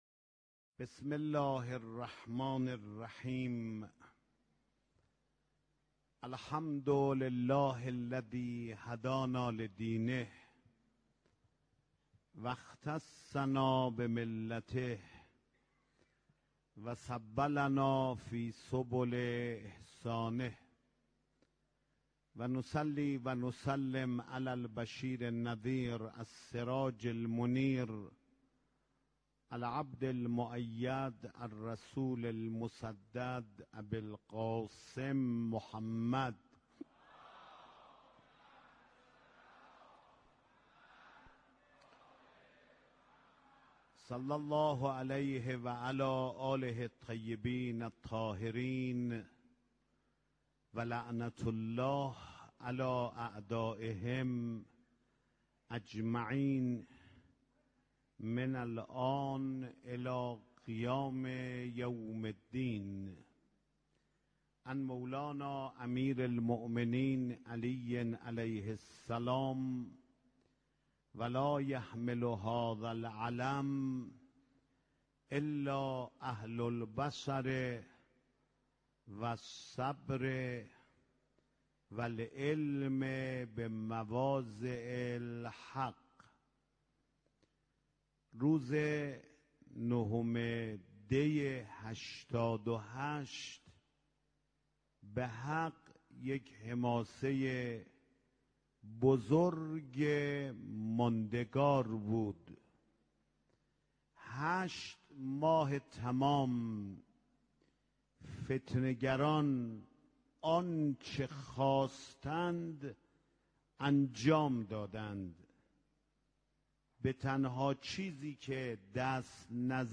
سخنرانی حجت الاسلام سید احمد خاتمی با موضوع عبرت های نهم دی